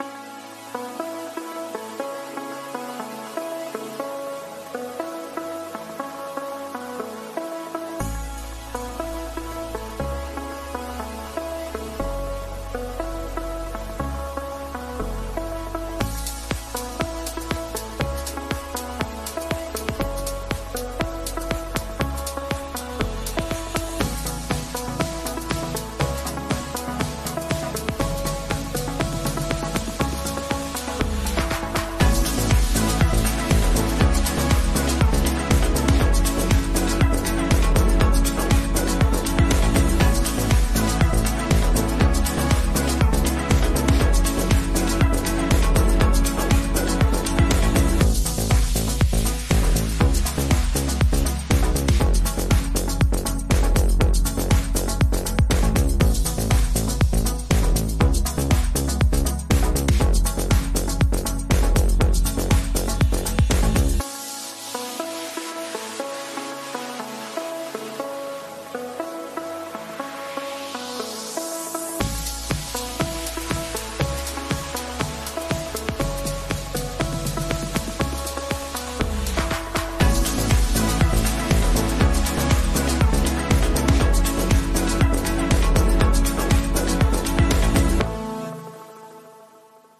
ElectroDance_0324_3.mp3